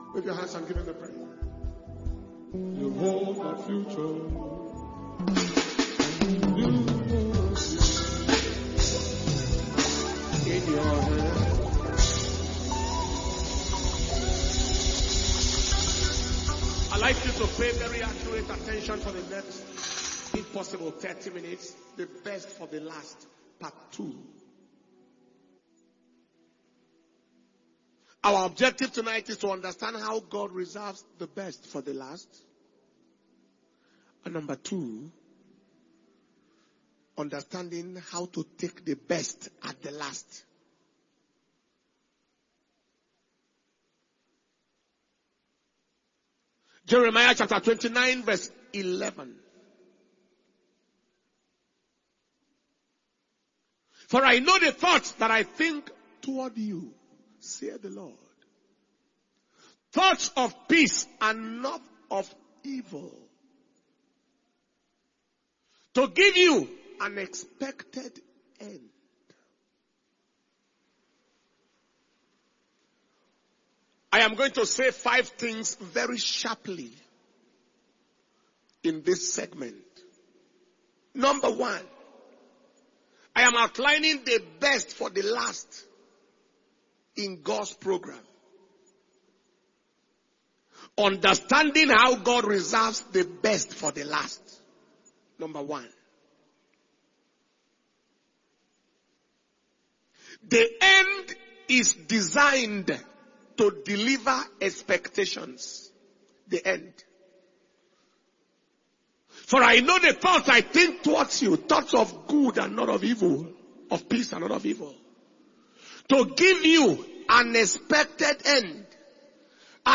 October 2023 Worship, Word And Wonders Night